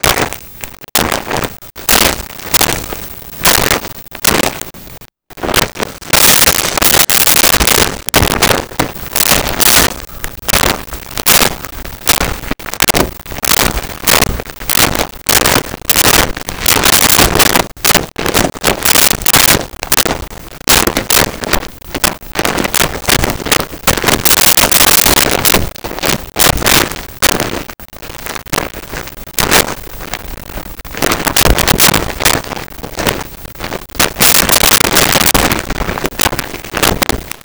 Footsteps Gravel
Footsteps Gravel.wav